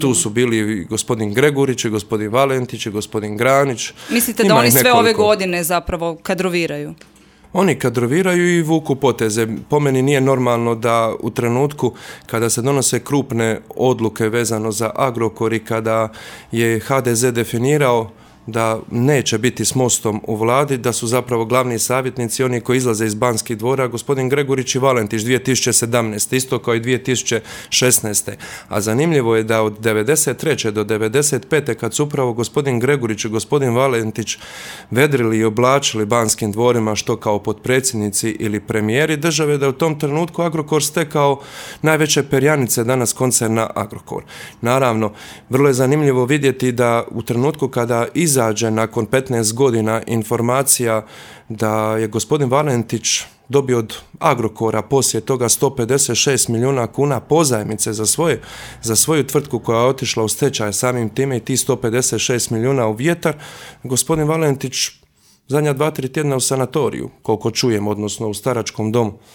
ZAGREB - U ekskluzivnom intervjuu za Media servis predsjednik Mosta i potpredsjednik Sabora Božo Petrov govorio je o slučaju Agrokor ali i Vladi Andreja Plenkovića.